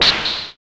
SI2 ARCADE.wav